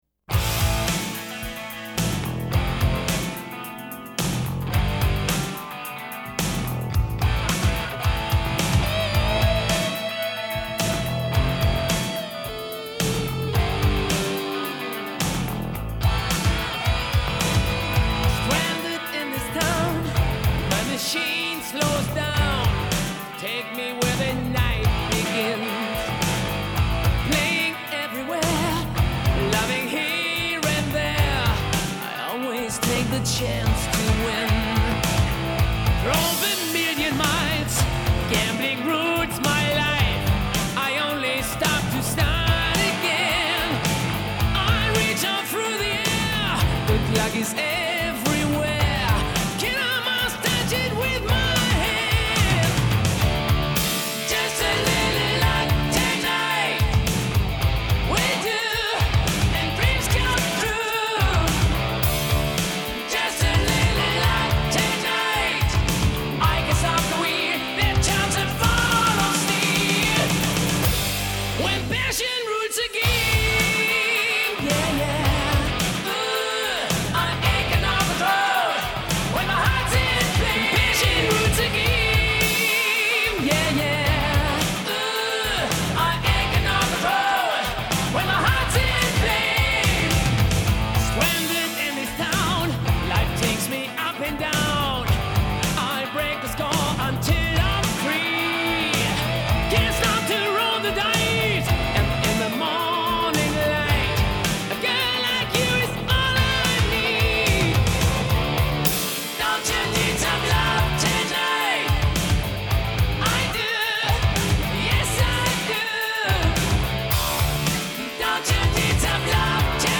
At first, I was a little dismayed by the production.